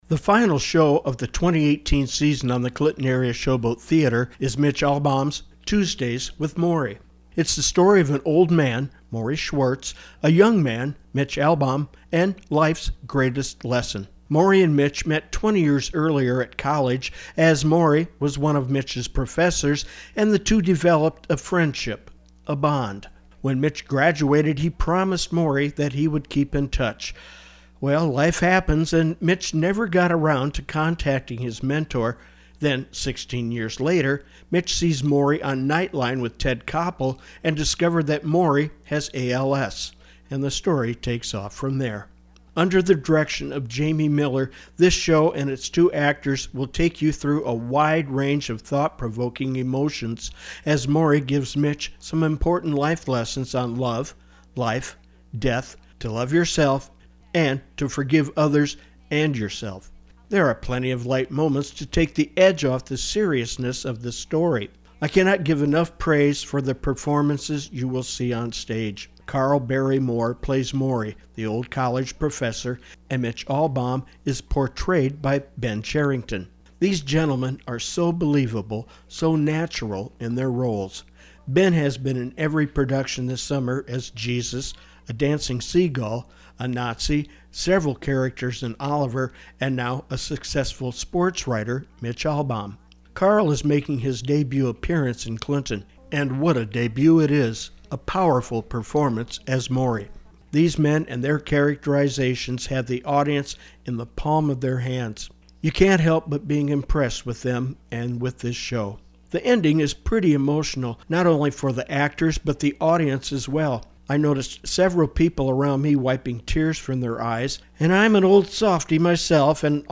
Tuesdays-With-Morrie-Review-8-9-18.mp3